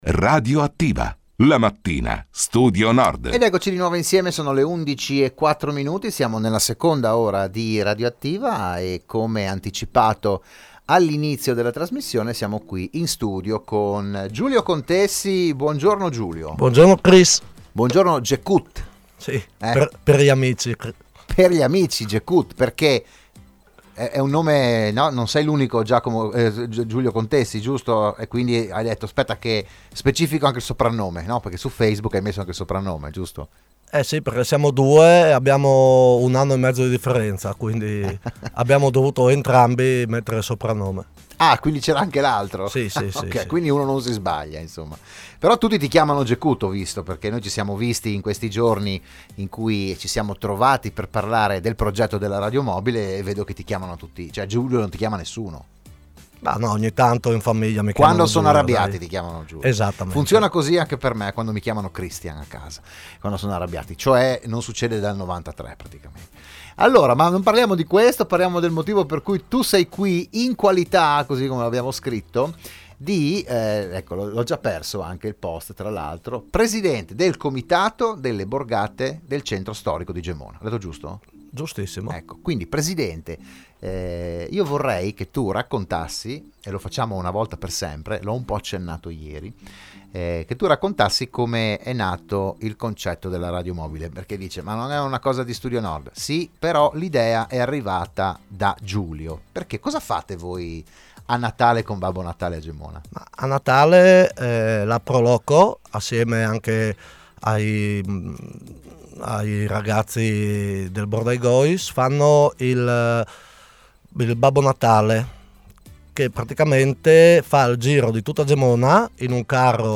L'AUDIO e il VIDEO dell'intervento a "RadioAttiva"